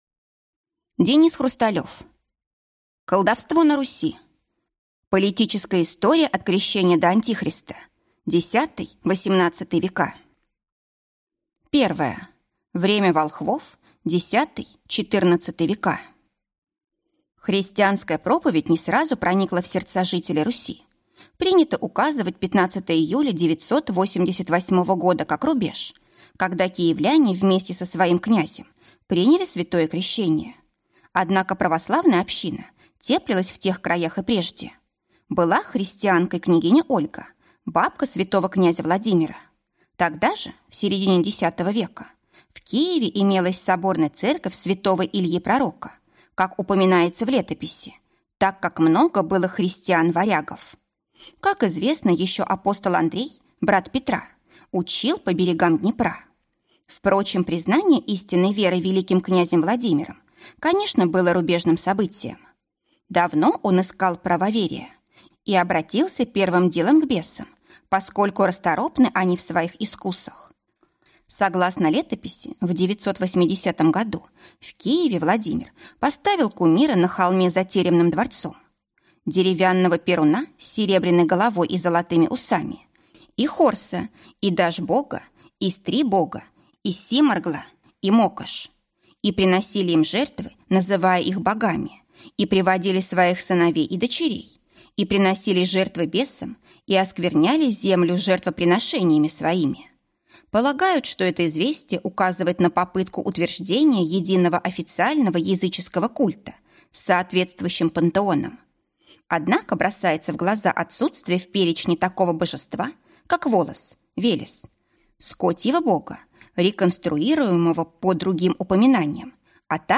Аудиокнига Колдовство на Руси. Политическая история от Крещения до «Антихриста» | Библиотека аудиокниг